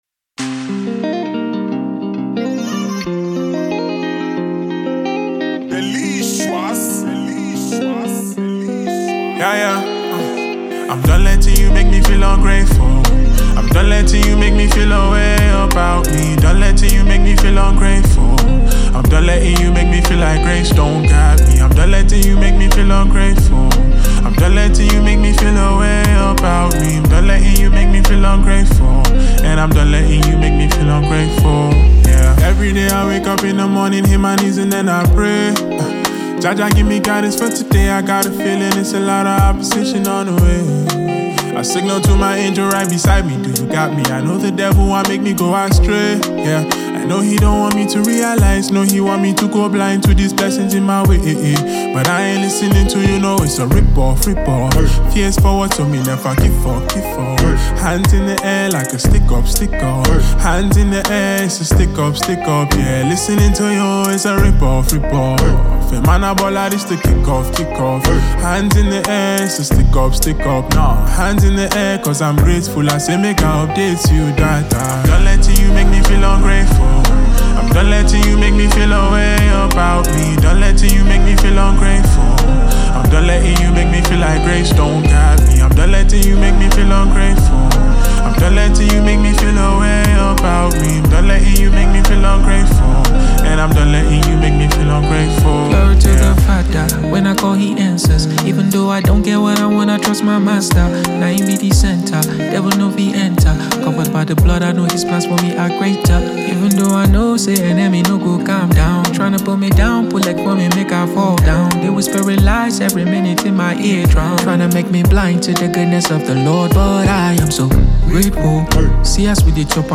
Afro Gospel single
Known for blending Afrobeat with Gospel
uplifting track